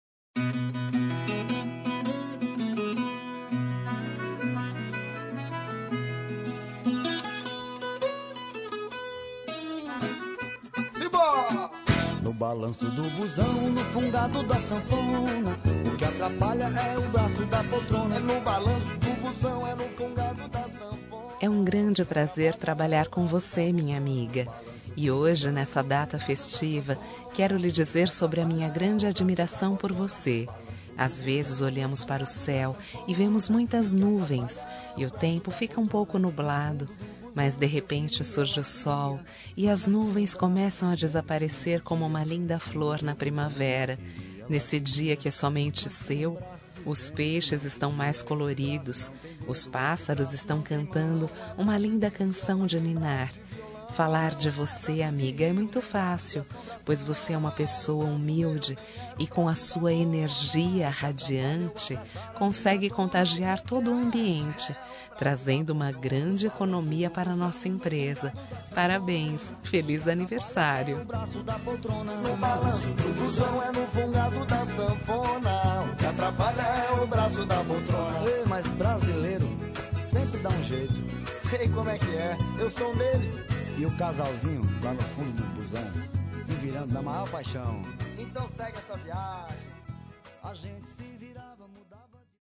Aniversário de Humor – Voz Feminina – Cód: 200101